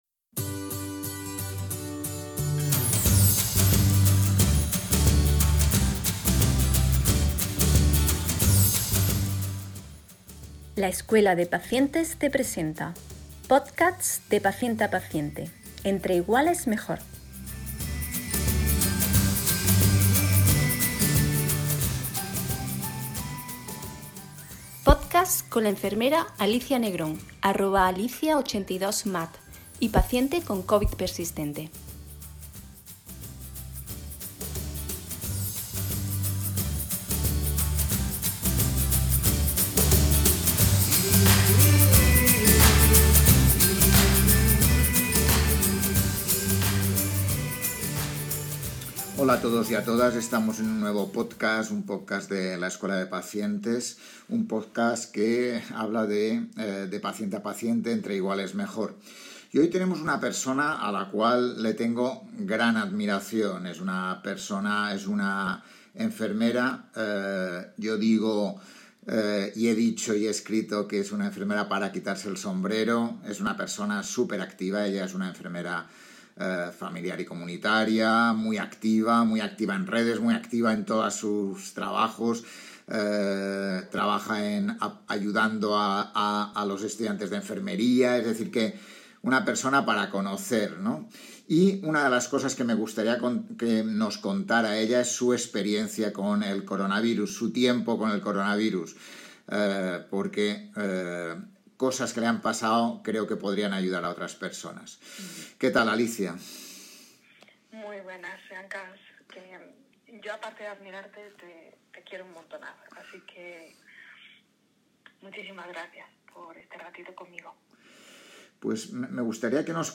Podcast con la enfermera
y paciente con COVID persistente.